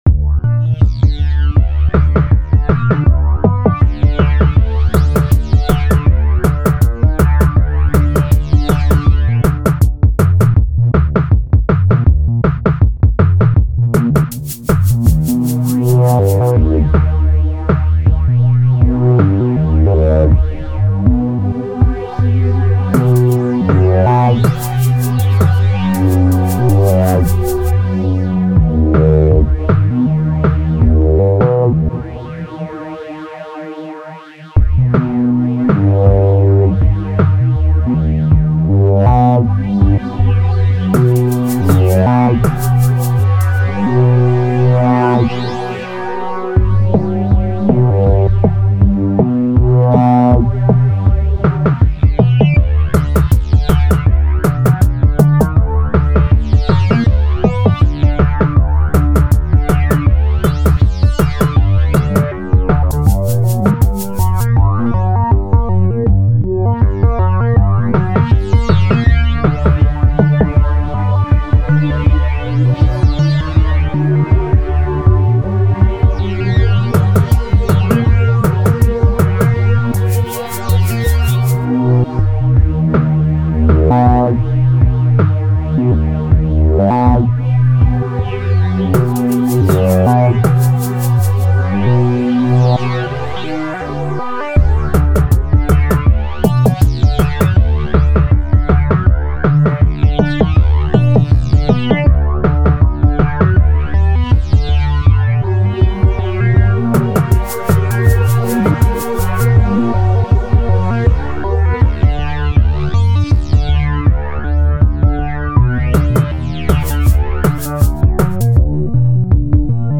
On obtient comme résultat une musique assez inhabituelle, structurée mais sans thème répétitif.